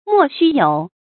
莫须有 mò xū yǒu
莫须有发音